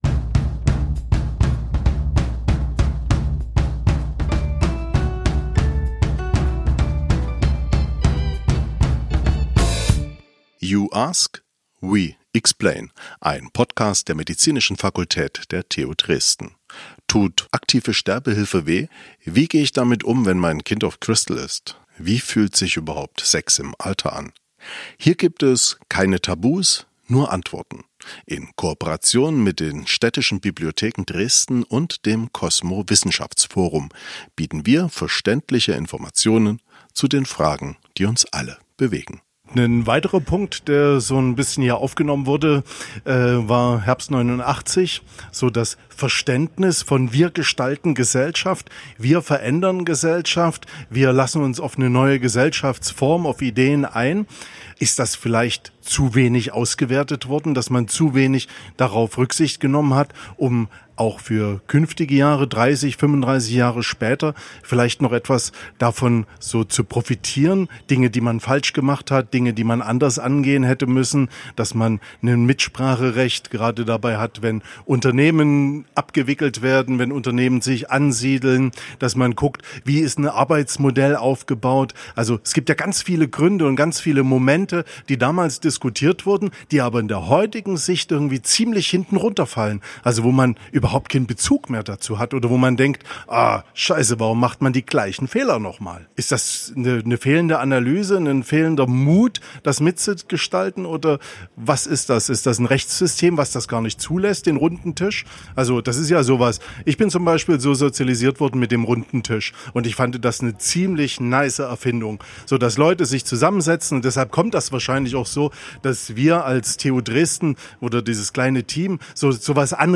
Wir beleuchten, welche Fehler in der Vergangenheit Konsequenzen hatten und wie wir daraus lernen können. Musikalische Begleitung
Live aufgenommen
auf dem Pirnaer Marktplatz vor dem Hofladen 15.